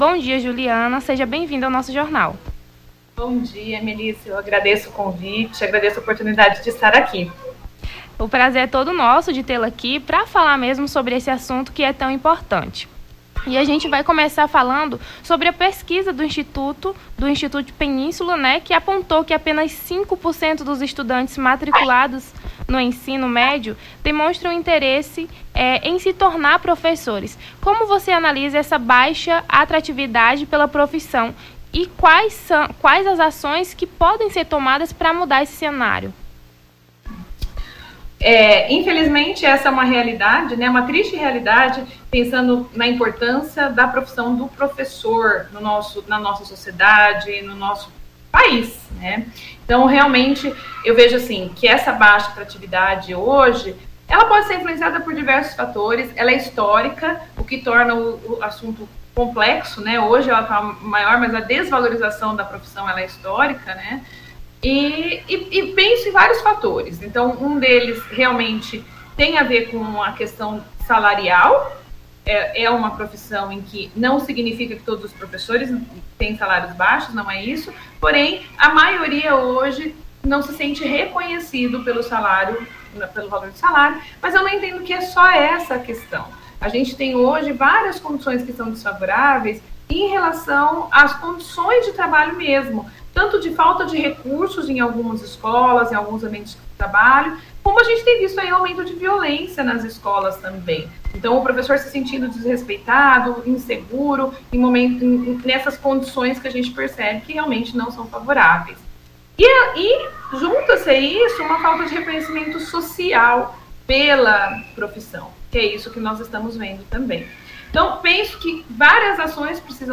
Nome do Artista - CENSURA - ENTREVISTA (PROFISSIONALIZACAO PRODESSORES) 22-08-23.mp3